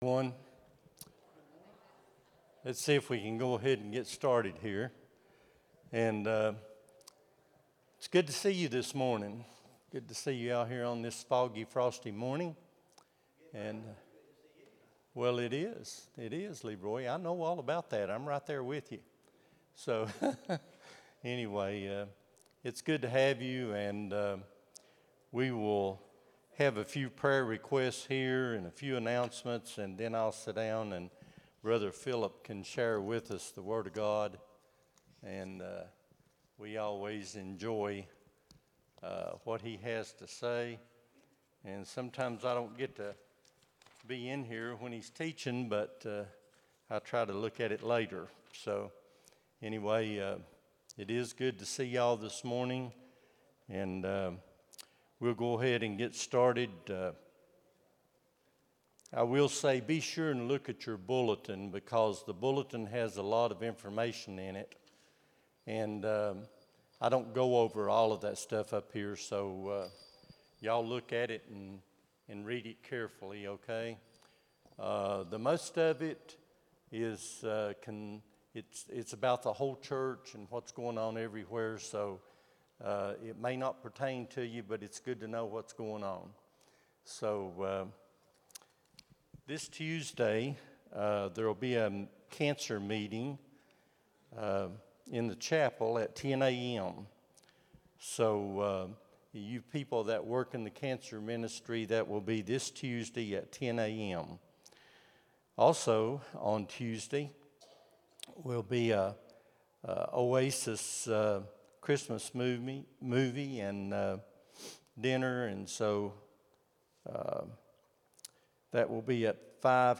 12-07-25 Sunday School | Buffalo Ridge Baptist Church